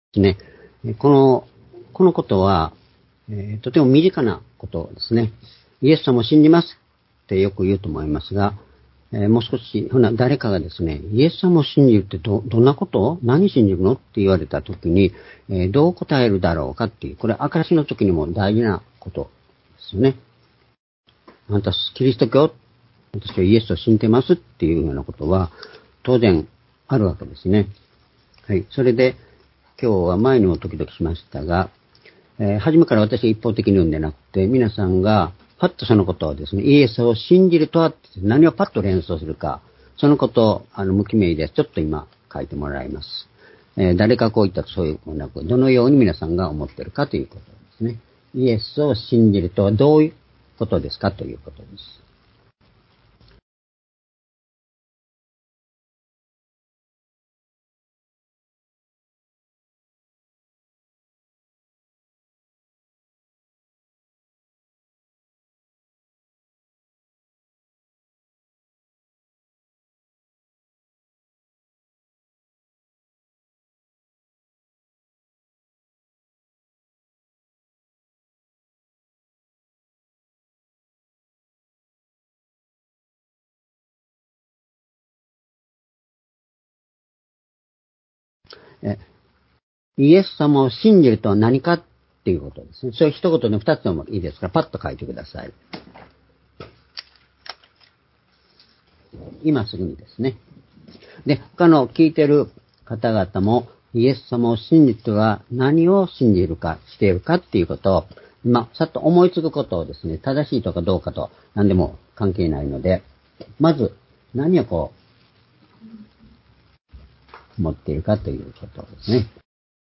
主日礼拝日時 ２０２３年7月2日（主日礼拝） 聖書講話箇所 「イエスを信じるとは、何を信じるのか」 ヨハネ１０の３１-４２ ※視聴できない場合は をクリックしてください。